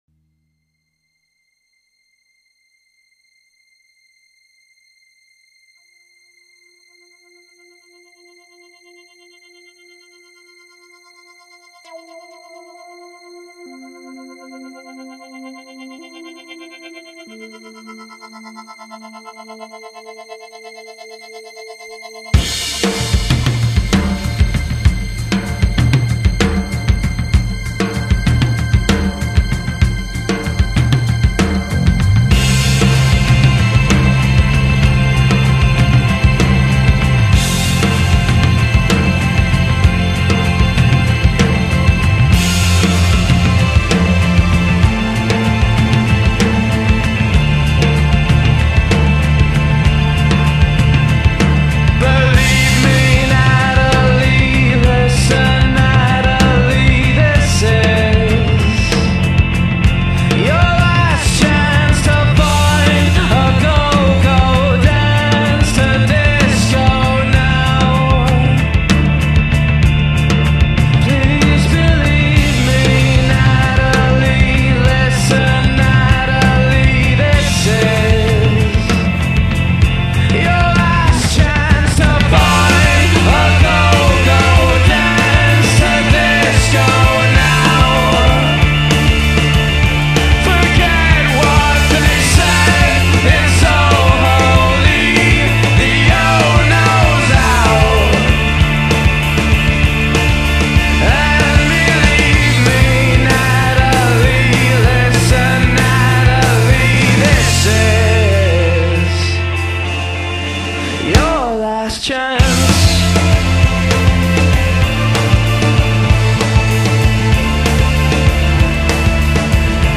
西洋音乐
弥漫80年代新浪潮之舞动节奏 散发阴郁沉稳的英伦摇滚风味